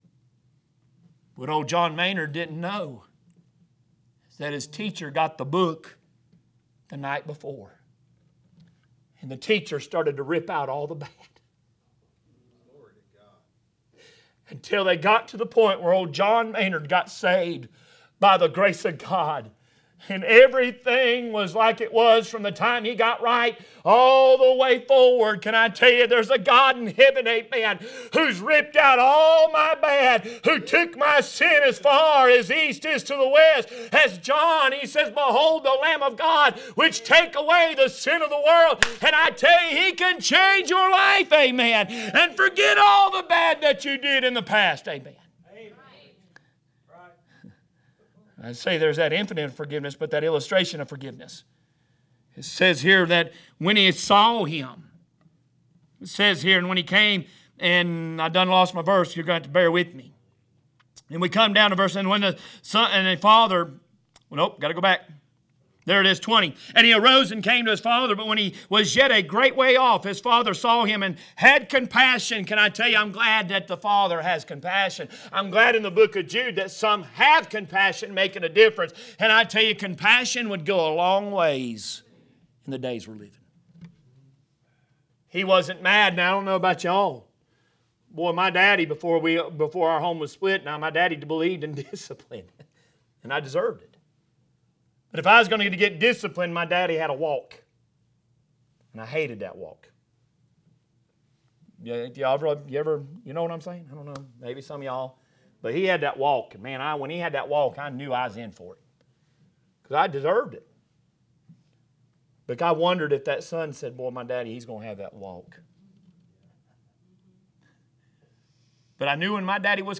A message from the series "Out of Series."